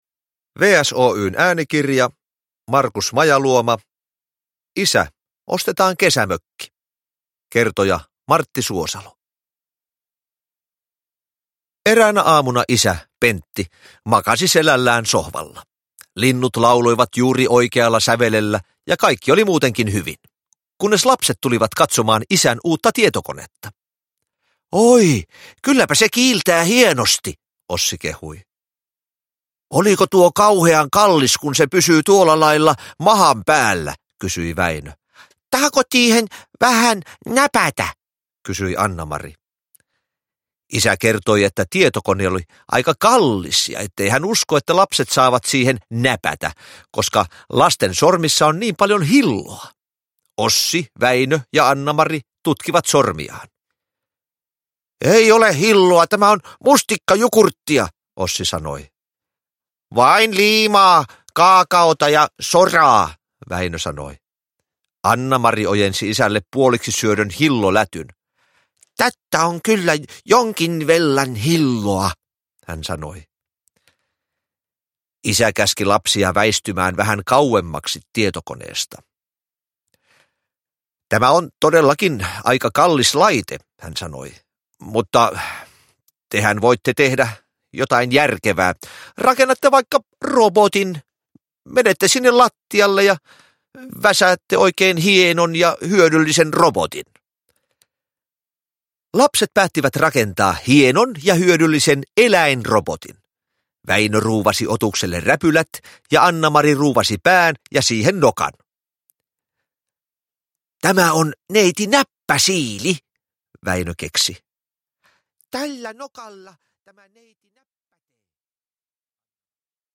Näyttelijä Martti Suosalo on kertojana sarjasta tehdyissä äänikirjoissa.
Uppläsare: Martti Suosalo